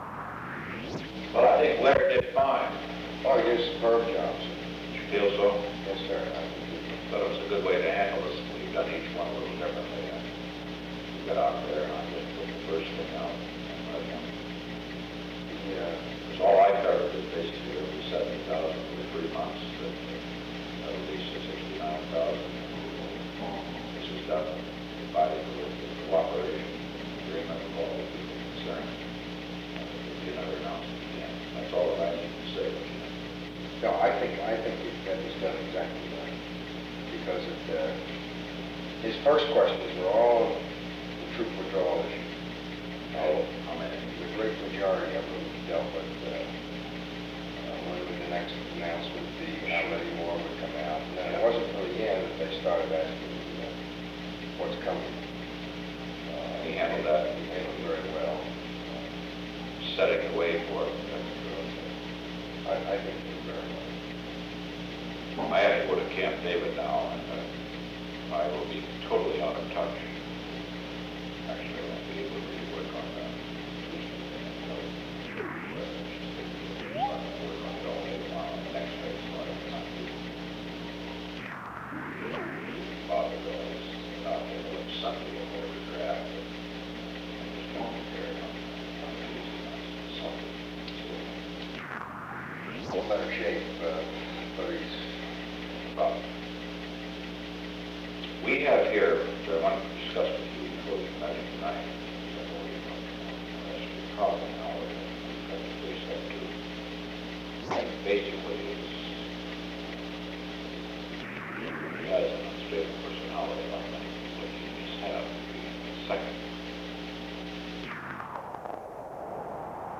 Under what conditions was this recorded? The Old Executive Office Building taping system captured this recording, which is known as Conversation 314-009 of the White House Tapes.